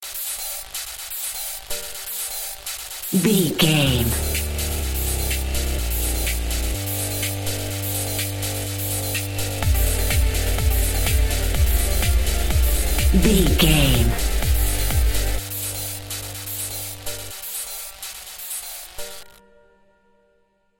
Epic / Action
Fast paced
Aeolian/Minor
Fast
dark
futuristic
groovy
aggressive
house
techno
trance
synth drums
synth leads
synth bass
upbeat